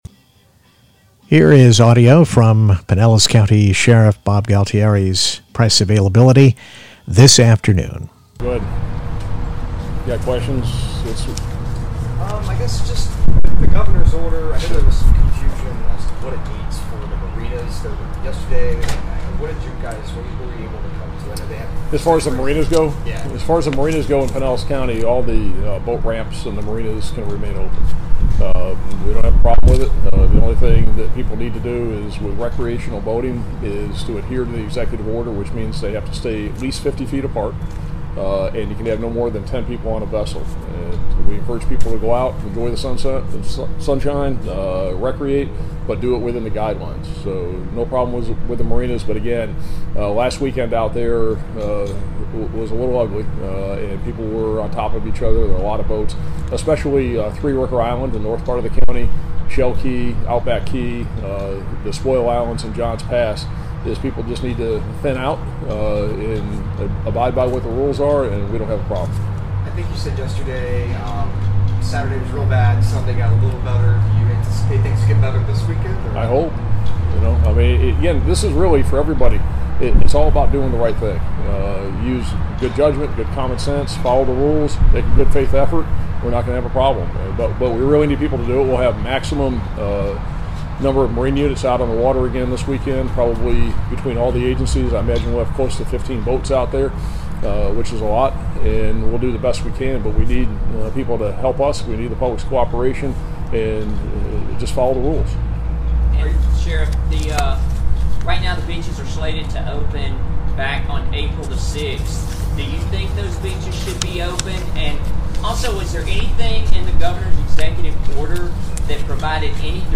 Pinellas County Sheriff Bob Gualtieri Press Conference 4-3-20